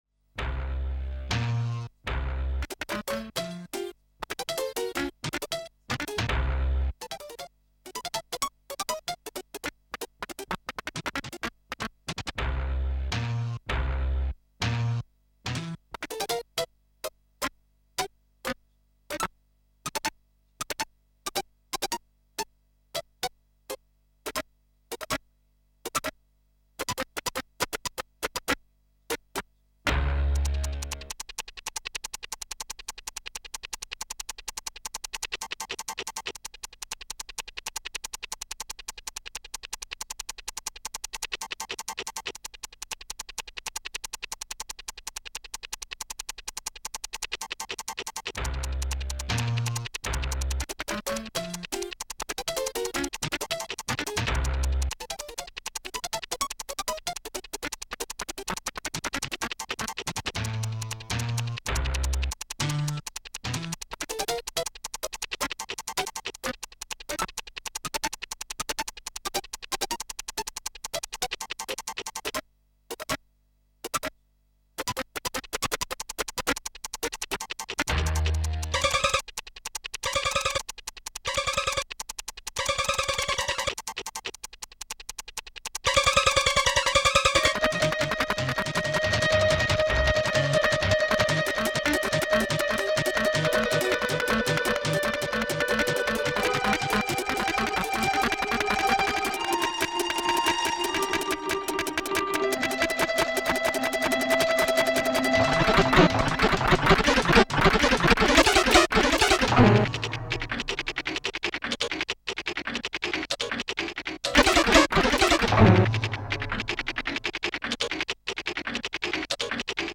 Realizada con un teclado Roland W-30. Grabada por el autor.